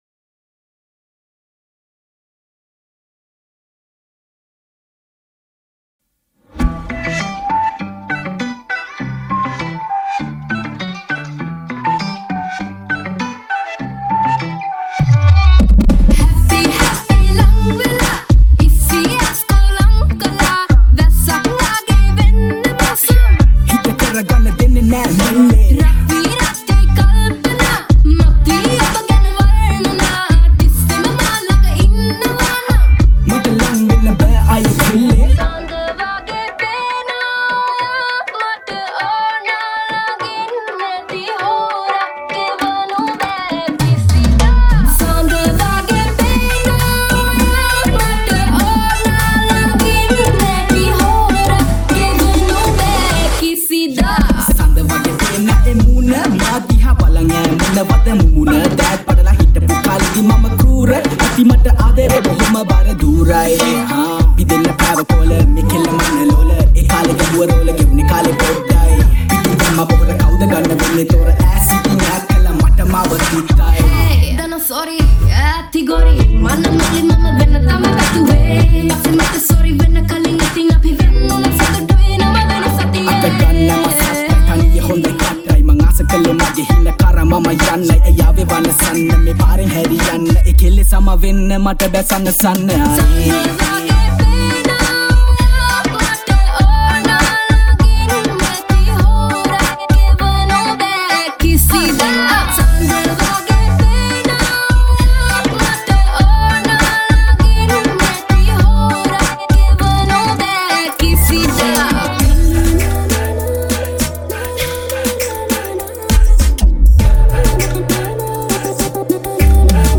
Sri 8D Music New Song